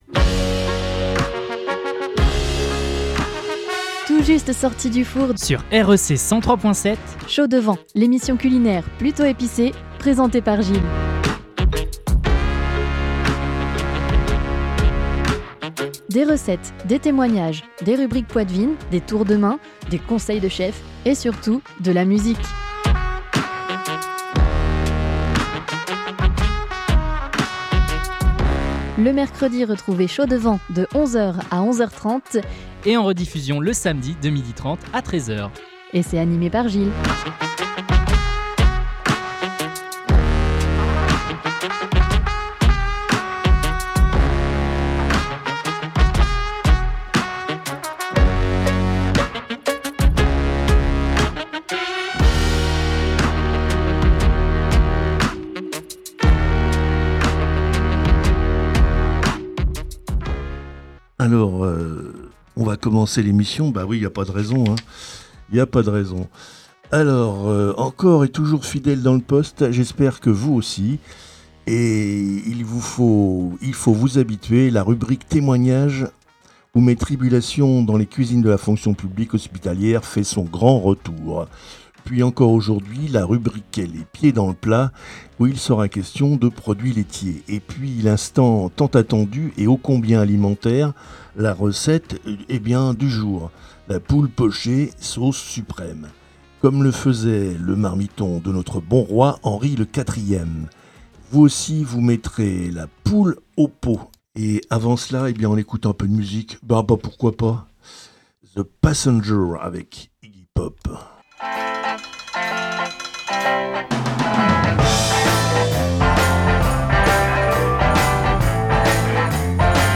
avec anecdotes, témoignages , rubriques , recettes avec des conseils de chef et forcément de la musique !